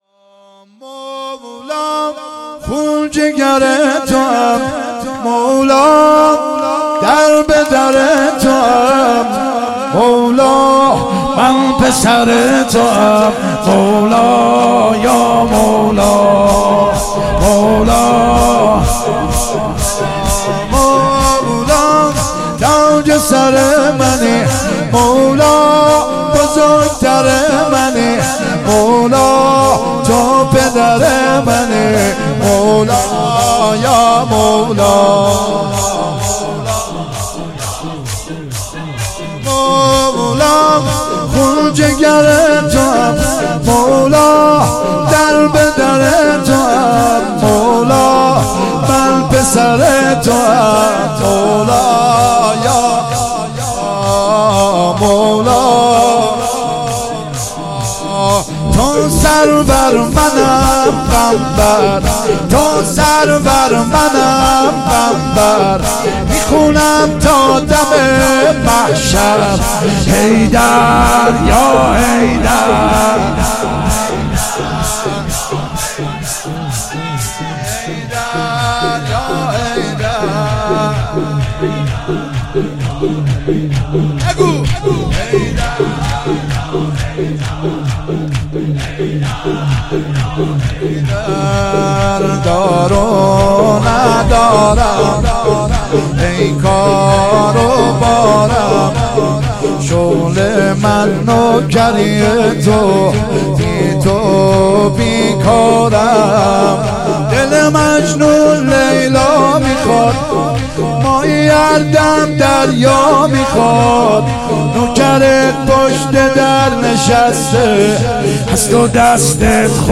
شور ( مولا خون جگر تو ام
دهه اول صفر 1441 شب اول